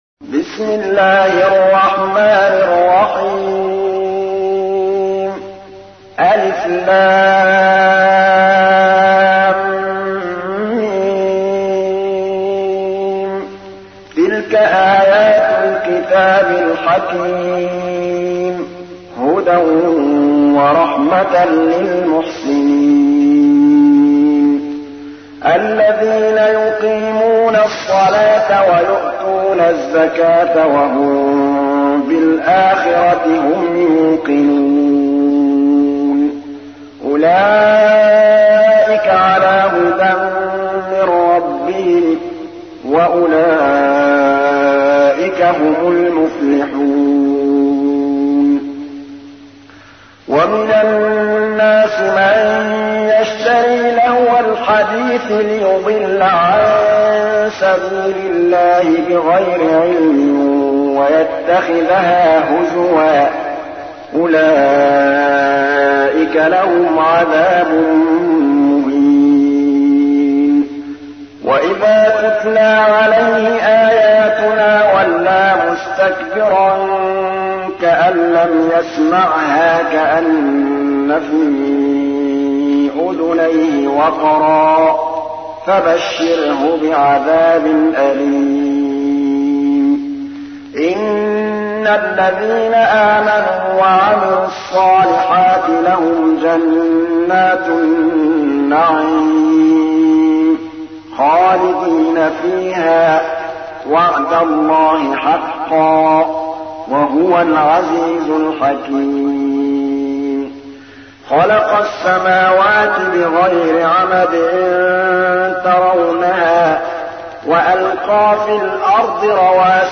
تحميل : 31. سورة لقمان / القارئ محمود الطبلاوي / القرآن الكريم / موقع يا حسين